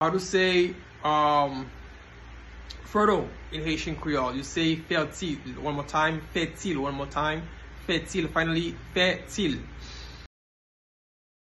Pronunciation:
Listen to and watch “Fètil” pronunciation in Haitian Creole by a native Haitian  in the video below:
Fertile-in-Haitian-Creole-Fetil-pronunciation-by-a-Haitian-teacher.mp3